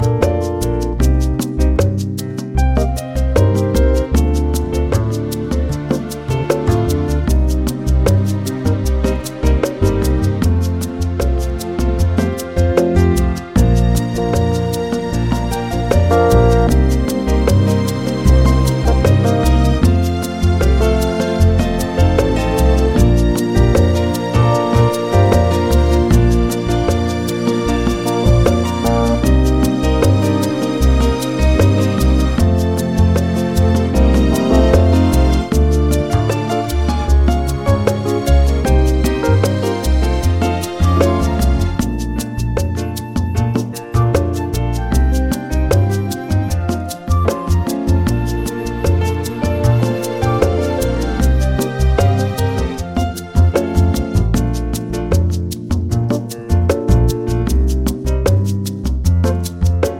Male Key of Bb